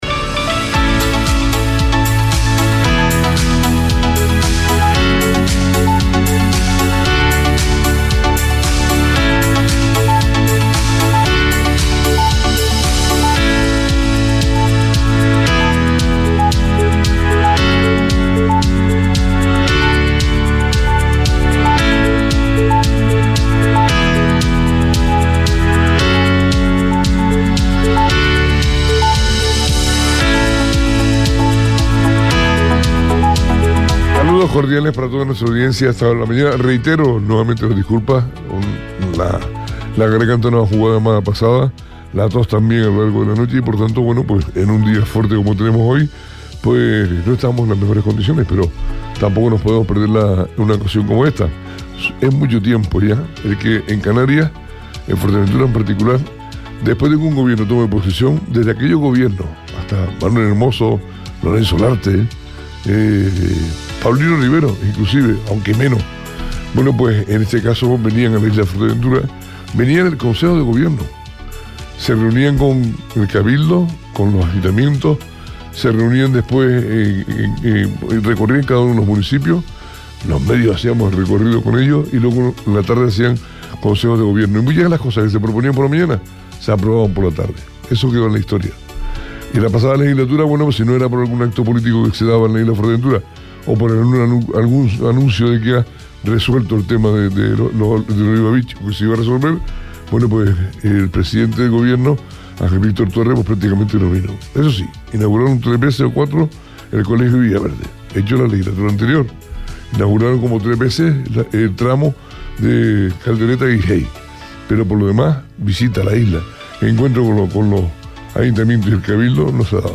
Entrevista a Fernando Clavijo – 20.07.23